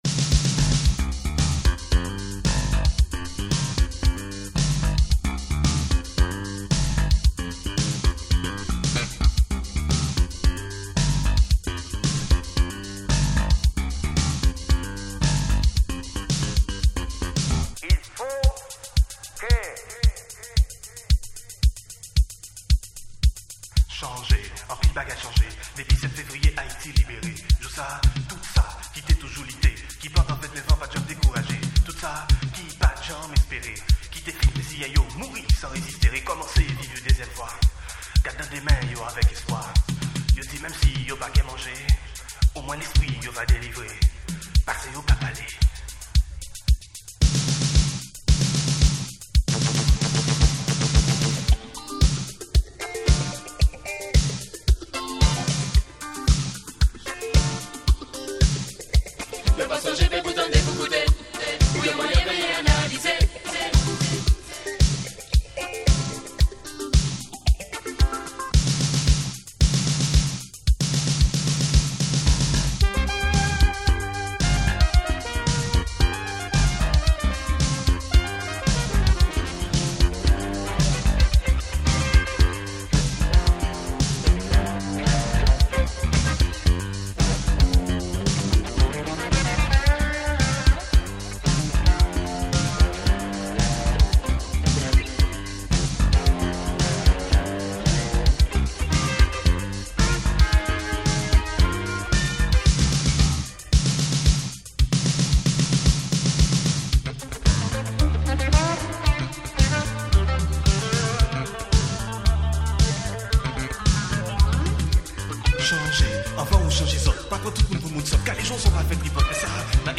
monster electro cosmic boogie
pure digital tropical madness !